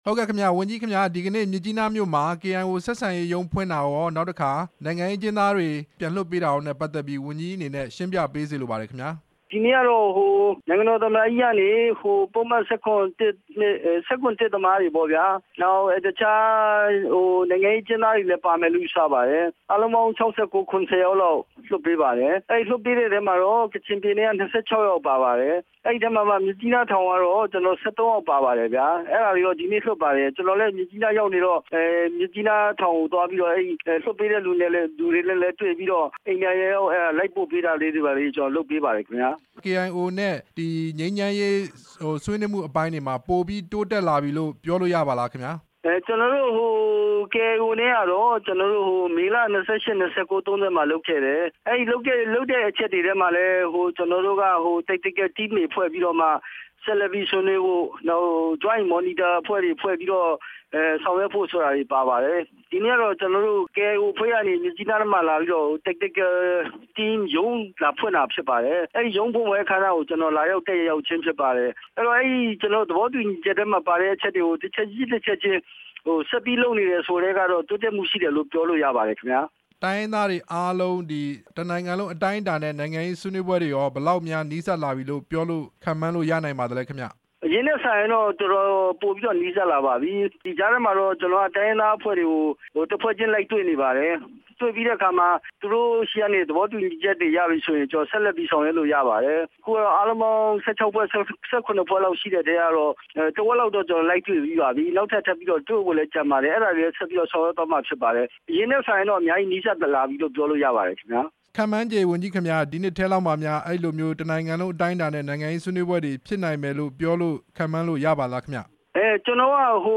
ပြည်ထောင်စုဝန်ကြီး ဦးအောင်မင်းနဲ့ မေးမြန်းချက်